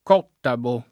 cottabo [ k 0 ttabo ]